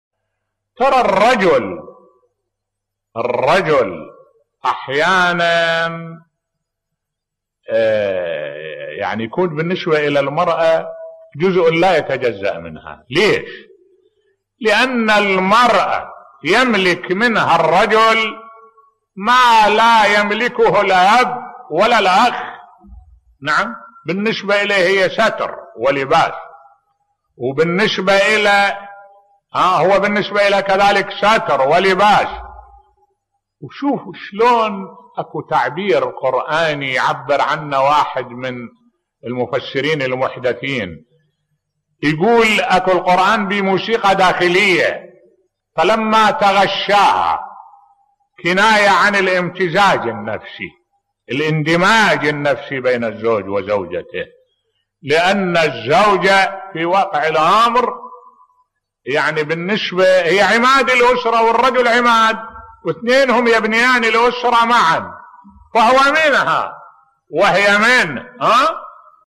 ملف صوتی أهمية الإندماج النفسي بين الزوج و الزوجة بصوت الشيخ الدكتور أحمد الوائلي